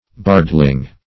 Bardling \Bard"ling\ (b[aum]rd"l[i^]ng), n. An inferior bard.